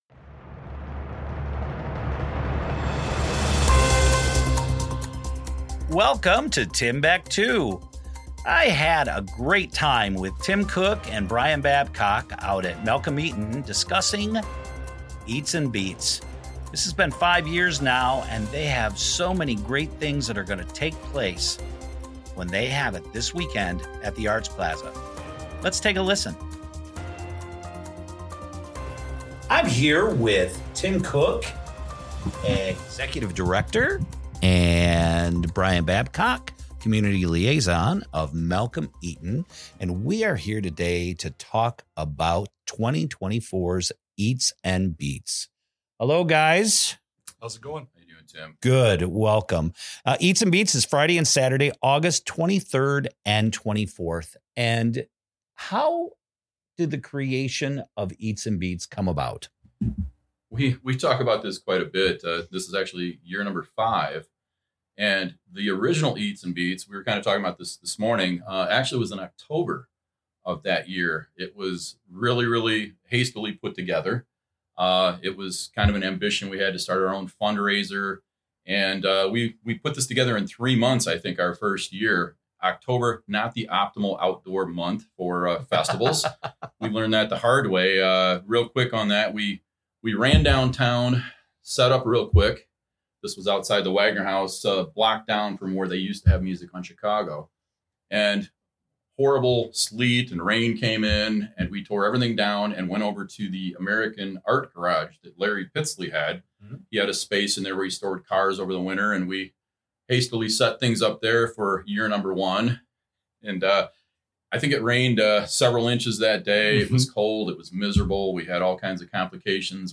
Freepod - Freepod Interviews Eats & Beats